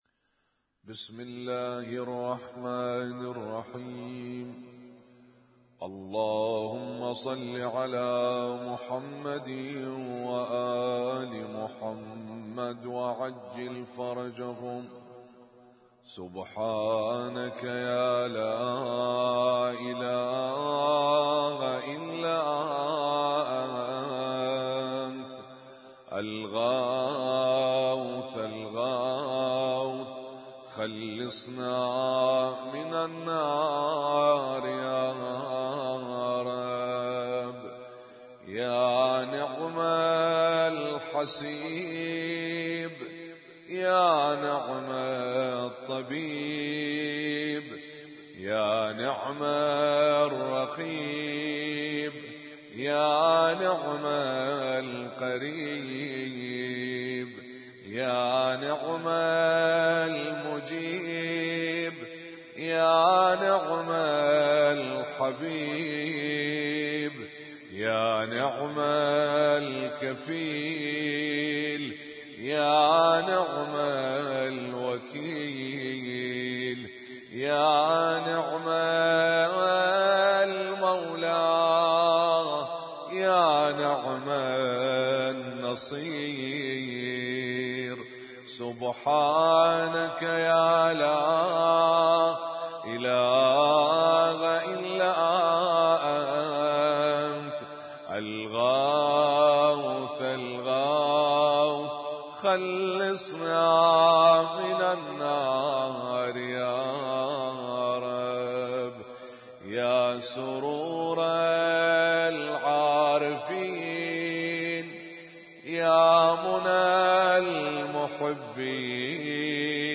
اسم التصنيف: المـكتبة الصــوتيه >> الادعية >> الادعية المتنوعة